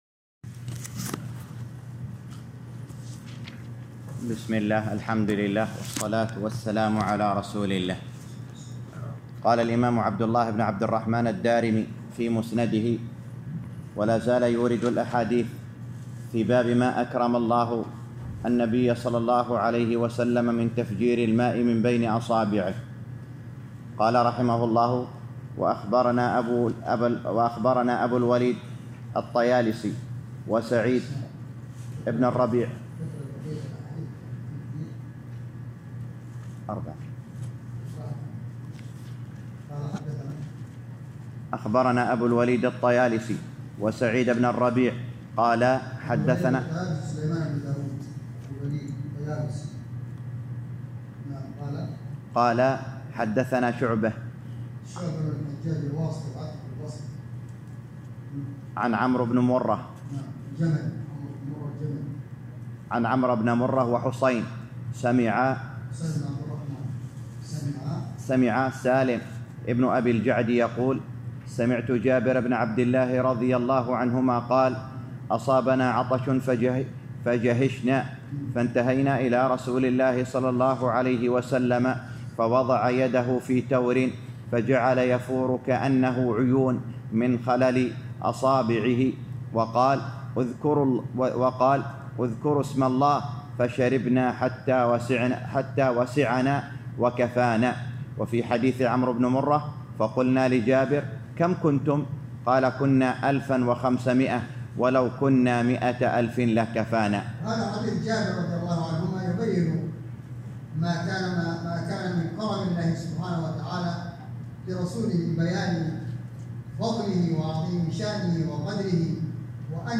الدرس الحادي عشر - شرح سنن الدارمي الباب الخامس _ 11